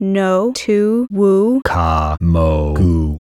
Both grammars involved sequences of syllables spoken by a female and male speaker - A syllables were spoken by the female and were ba, di, yo, tu, la, mi, no, or wu; B syllables were spoken by the male and were pa, li, mo, nu, ka, bi, do, or gu.
no-tu-wu-ka-mo-gu.wav